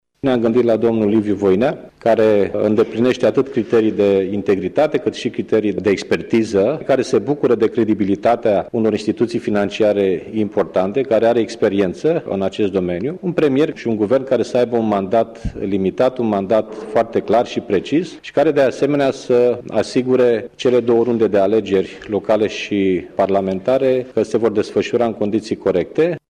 Liviu Dragnea şi calităţile pe care social-democraţii le-au identificat la Liviu Voinea: